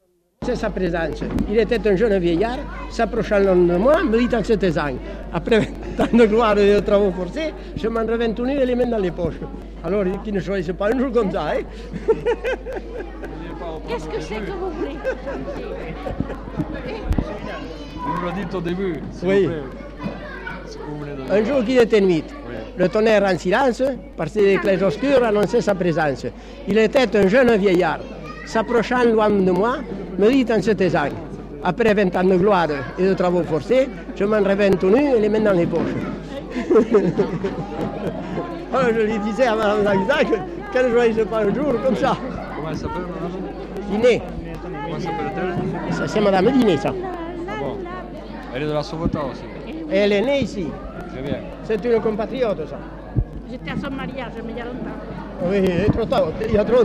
Monologue Centre culturel.